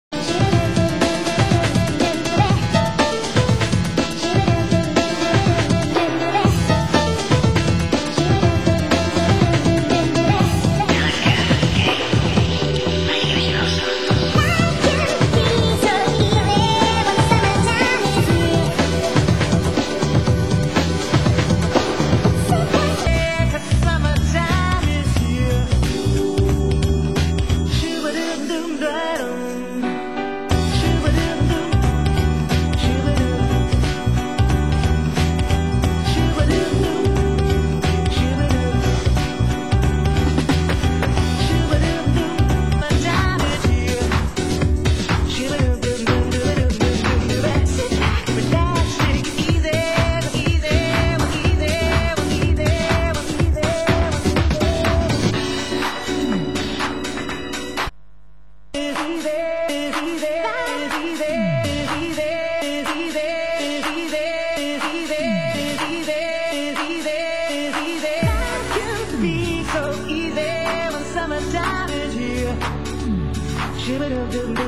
Genre: House
Genre: UK House